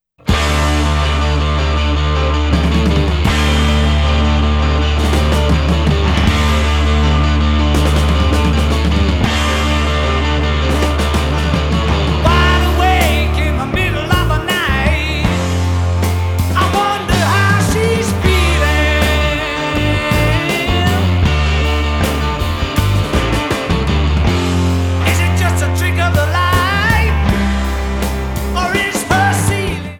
1996 Japan CD (Remix)   2011 Japan CD (Original Mix)